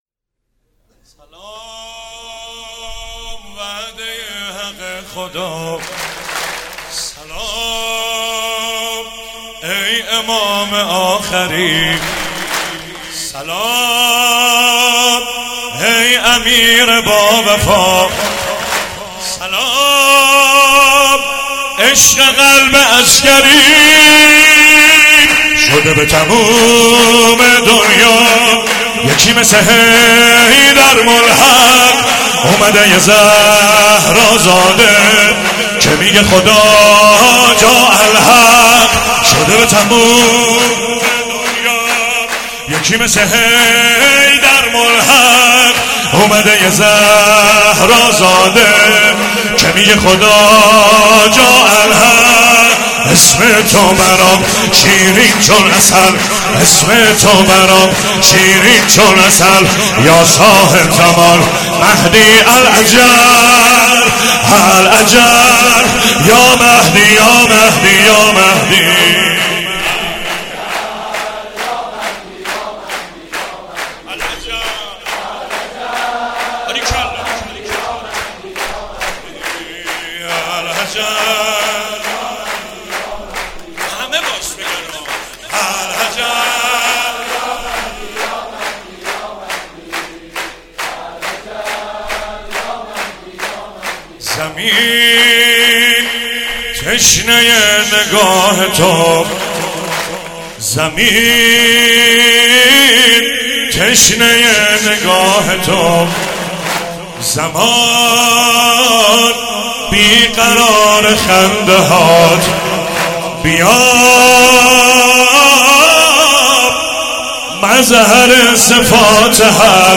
شور مولودی